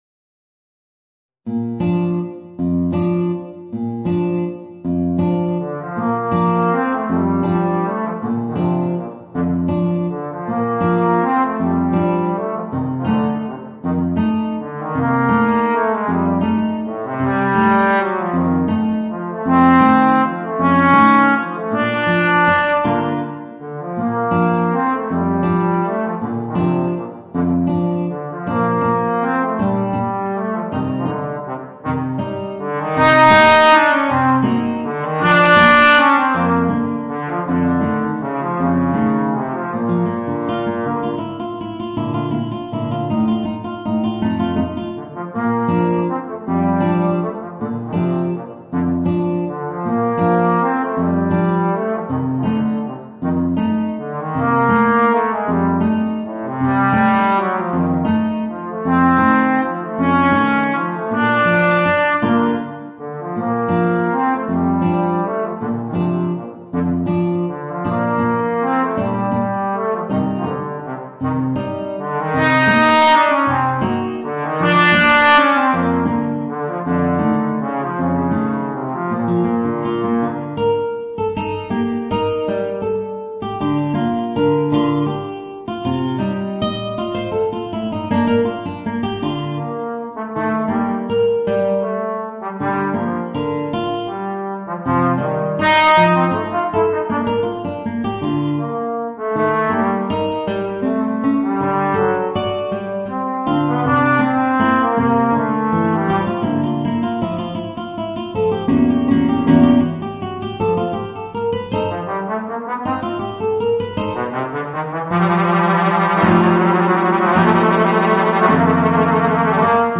Voicing: Guitar and Trombone